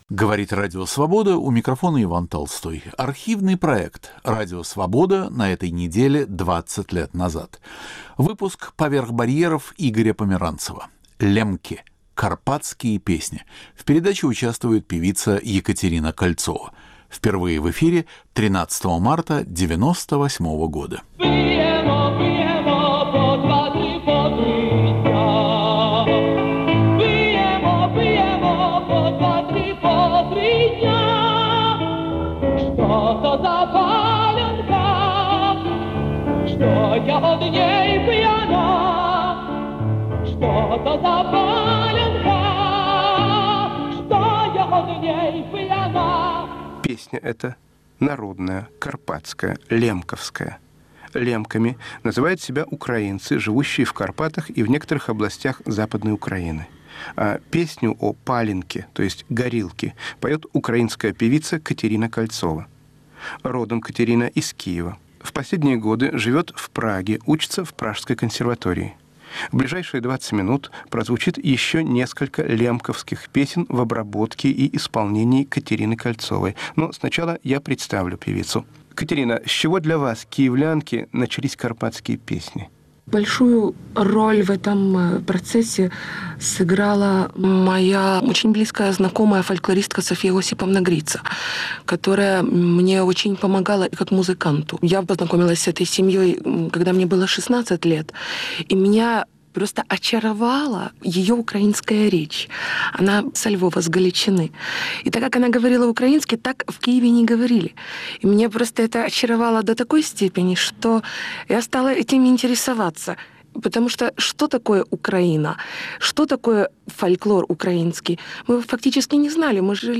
Поэты, чьи голоса звучали на волнах Свободы, читают свои стихи